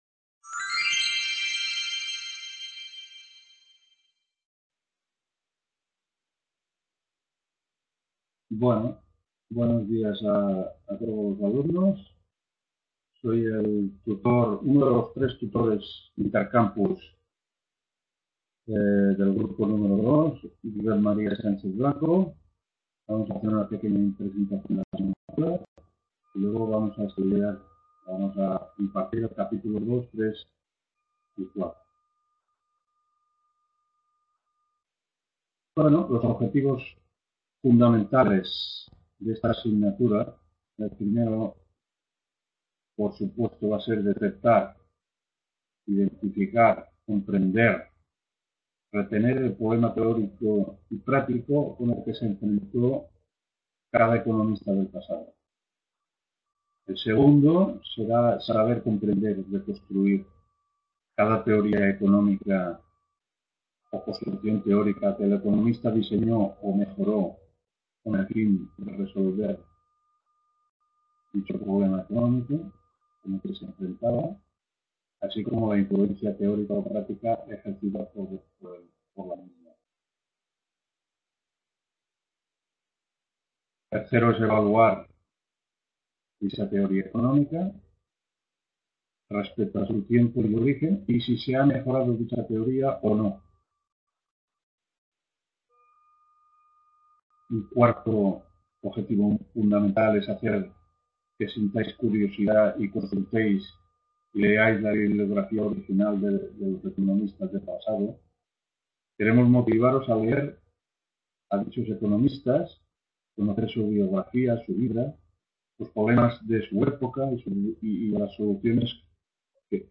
1ª TUTORIA INTERCAMPUS HISTORIA del PENSAMIENTO ECONÓMICO